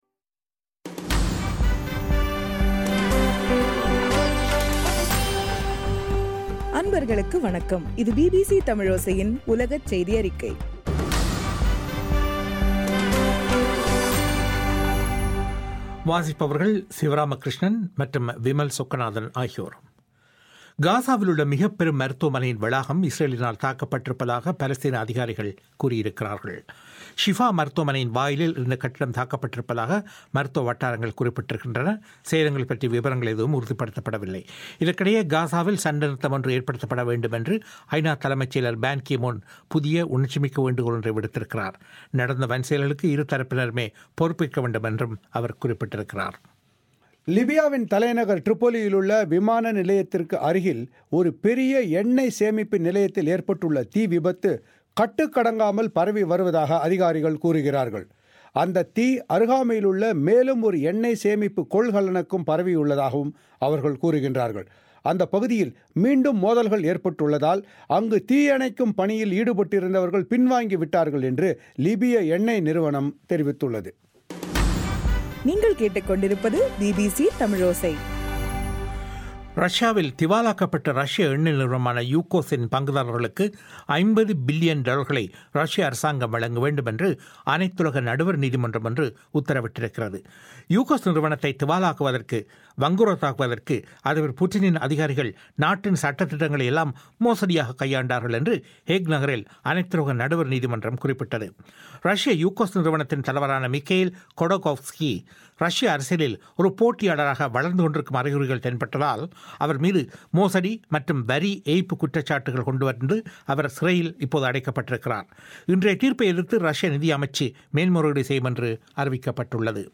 ஜூலை 28 2014 பிபிசி தமிழோசையின் உலகச் செய்திகள்